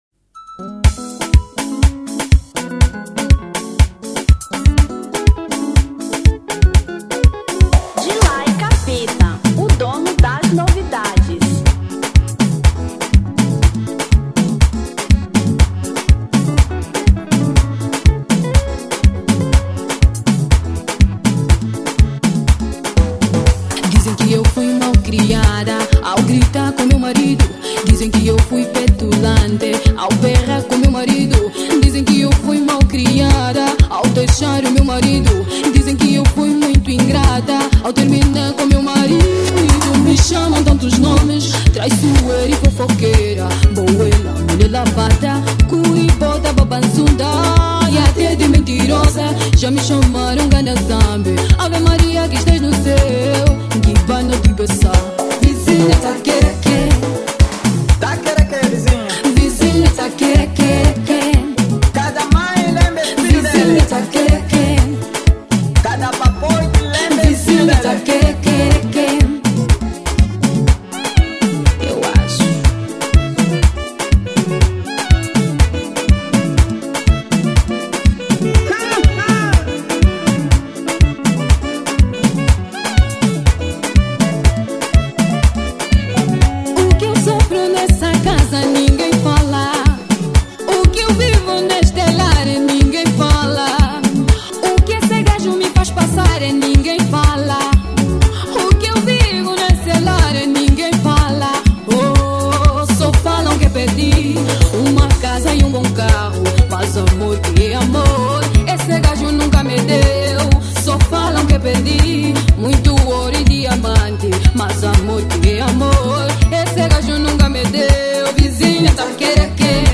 Semba 2025